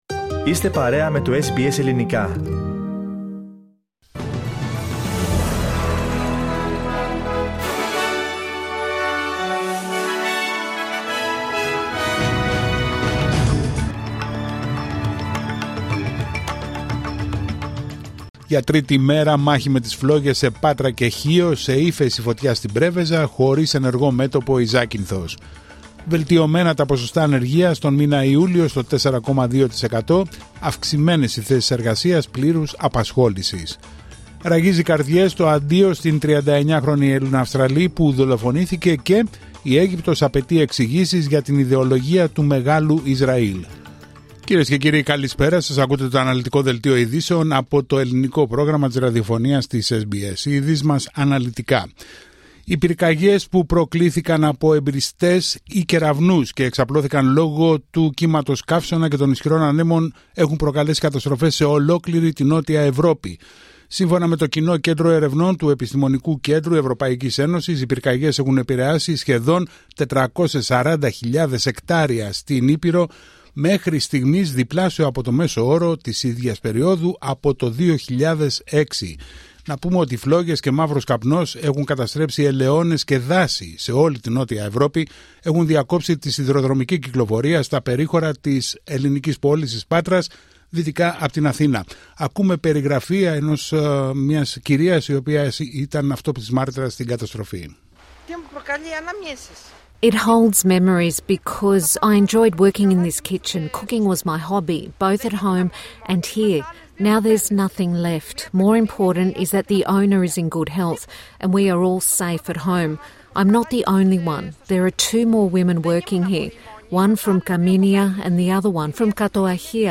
Δελτίο ειδήσεων Πέμπτη 14 Αυγούστου 2025